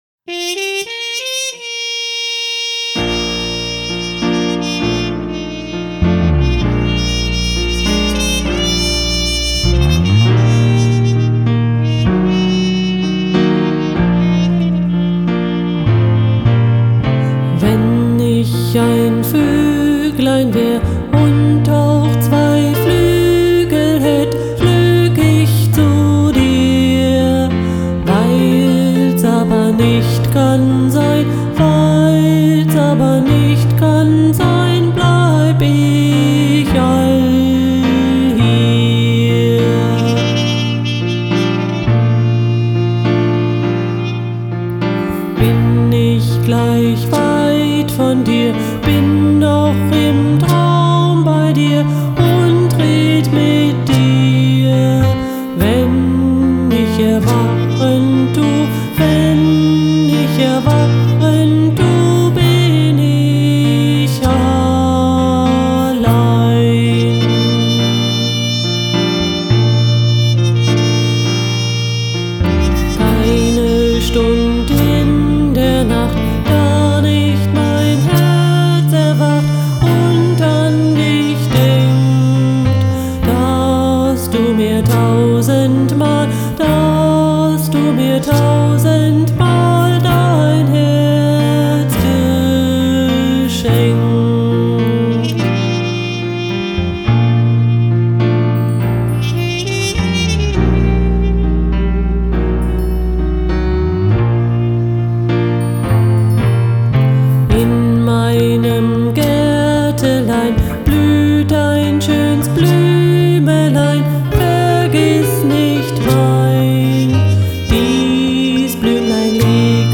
Traditionelle Lieder
Trompete im Stil von Miles Davis